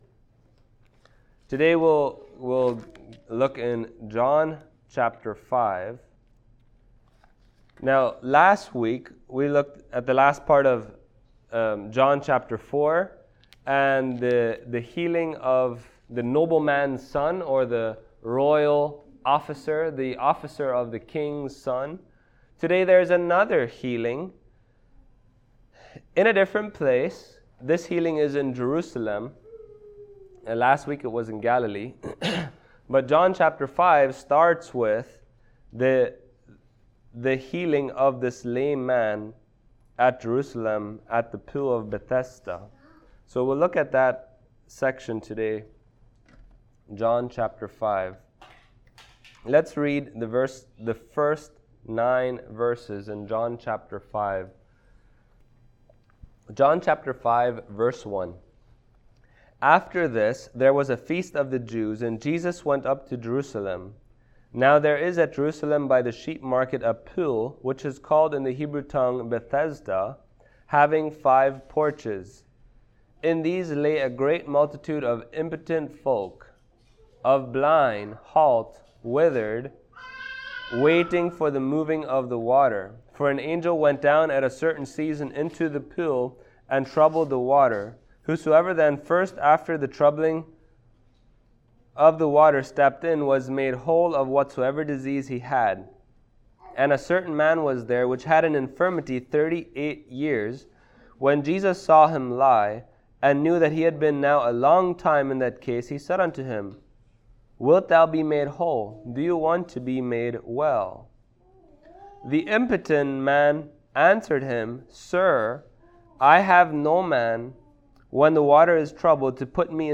John Passage: John 5:1-9 Service Type: Sunday Morning Topics